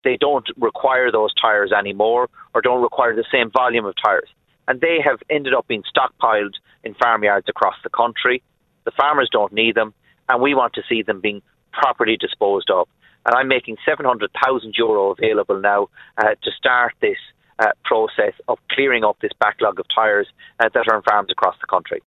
Environment Minister Denis Naughten says collection points are being set up around the country – starting today at Cootehill Mart in County Cavan: